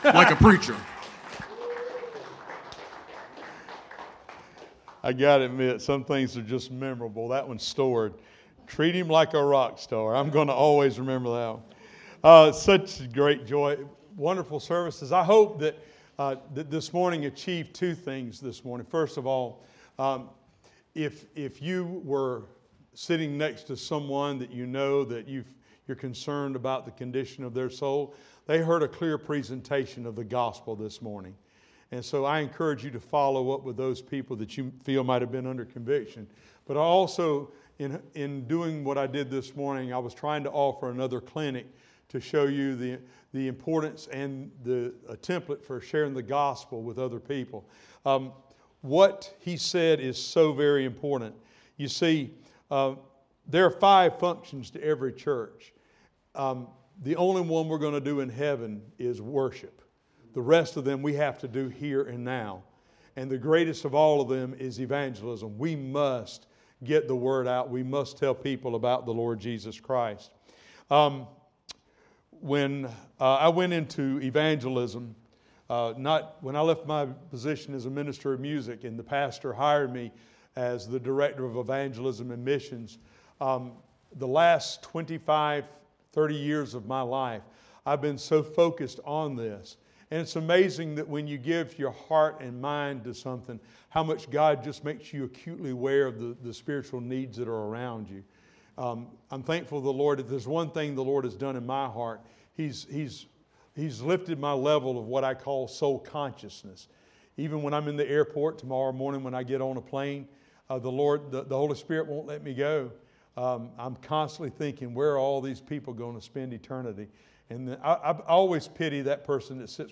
Evening Service – Guest Speaker